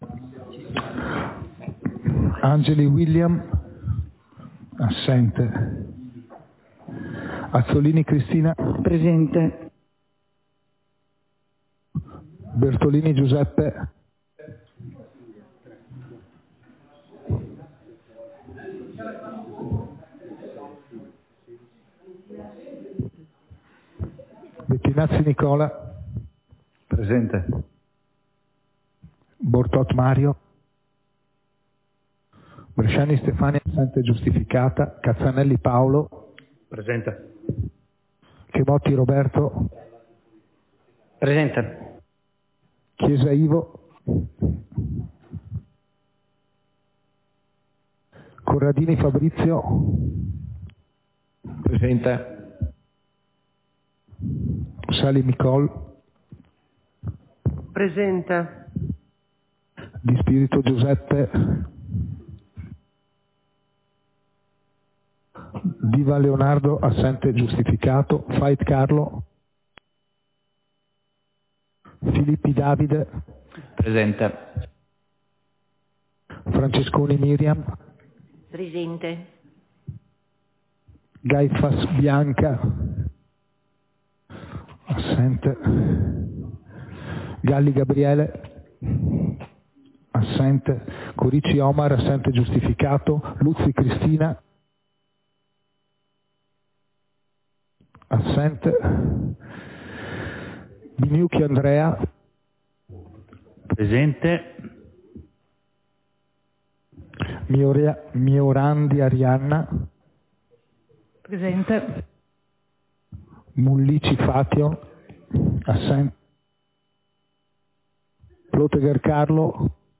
Seduta del consiglio comunale - 12.12.2023